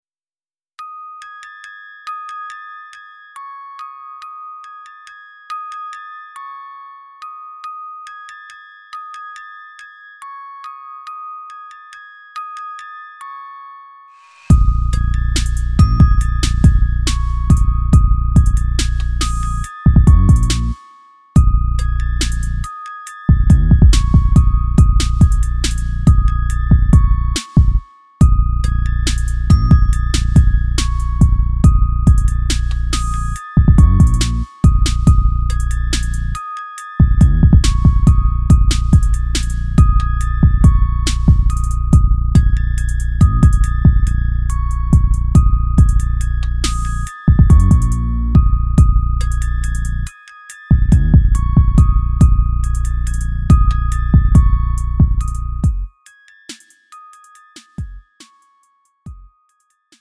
음정 -1키 4:11
장르 가요 구분 Lite MR